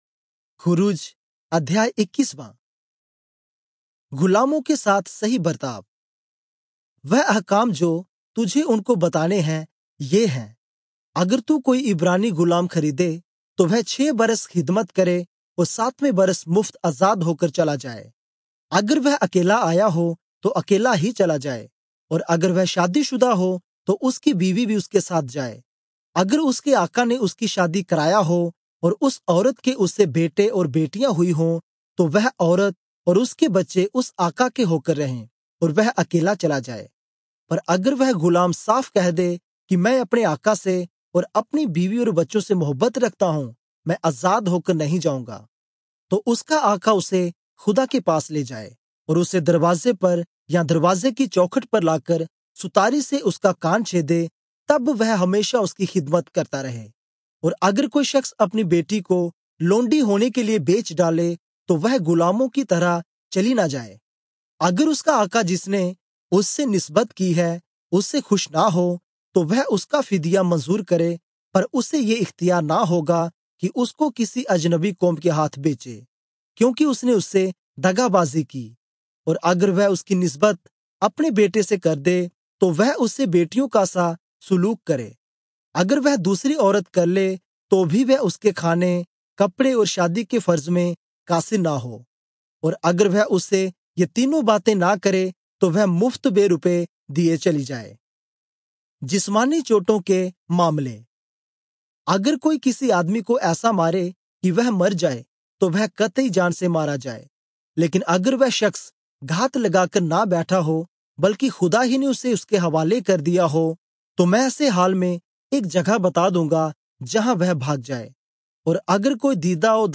Urdu Audio Bible - Exodus 37 in Irvur bible version